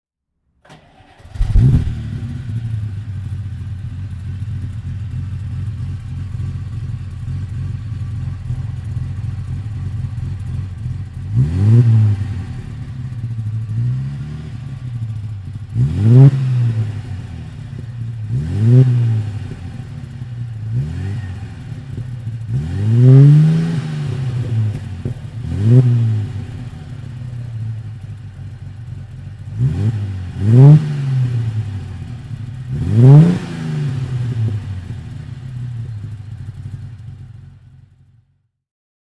Motorsounds und Tonaufnahmen zu Alfa Romeo Fahrzeugen (zufällige Auswahl)
Alfa Romeo 6 C 2500 Gran Turismo (1951) - Starten und Leerlauf
Alfa_Romeo_6C_1951.mp3